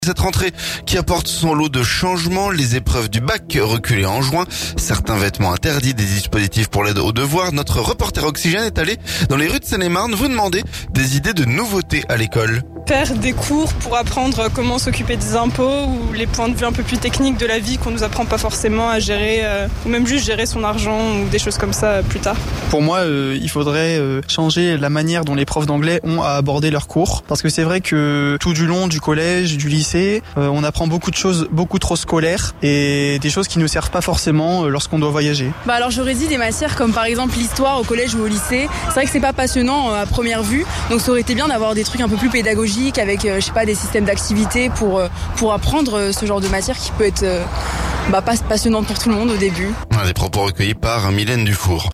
Notre reporter Oxygène est allée dans les rues de Seine-et-Marne vous demander des idées de nouveauté à l'école.